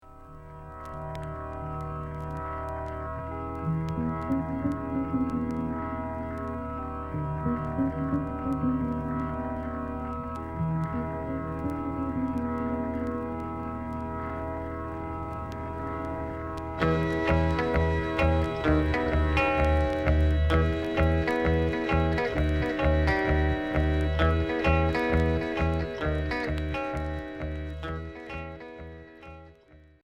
Pop Rock Premier 45t retour à l'accueil